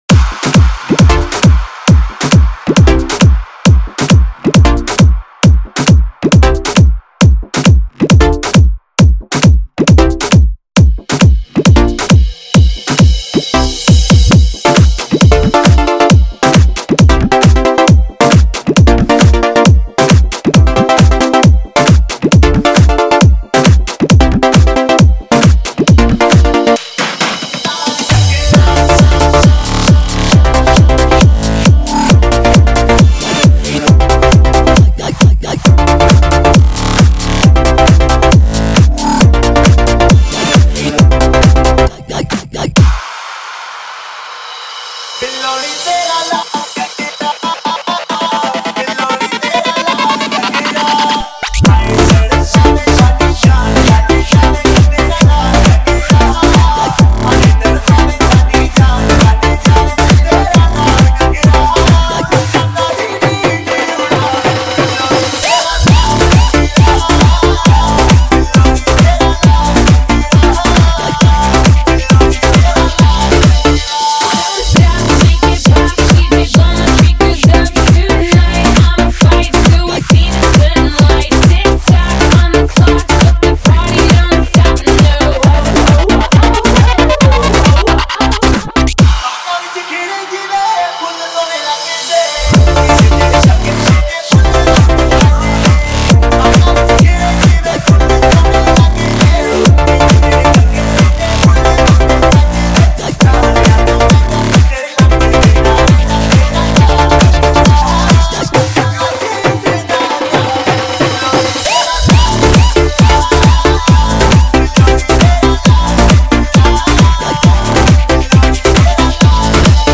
HomeMp3 Audio Songs > Others > Latest DJ-Mixes (August 2013)